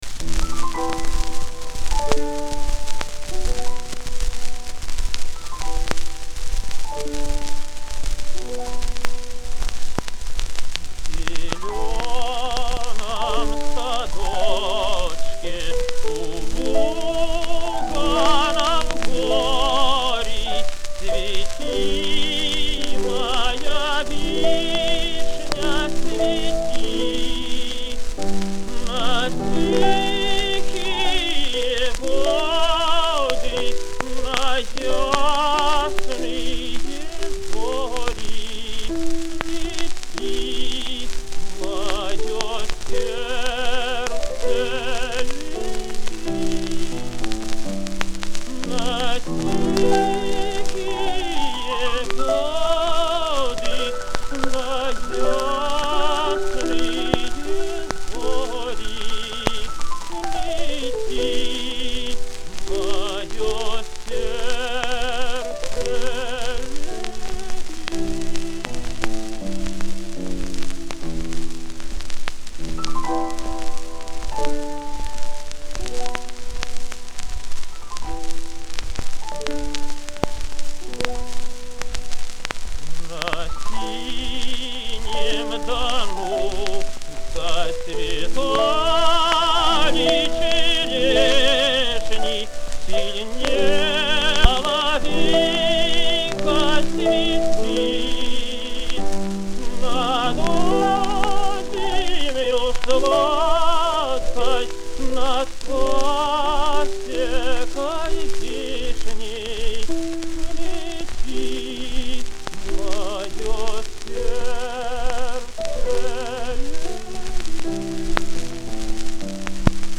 Написана в стиле романса.